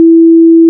**🔊 SFX PLACEHOLDERS (23 WAV - 1.5MB):**
**⚠  NOTE:** Music/SFX are PLACEHOLDERS (simple tones)
stone_mine.wav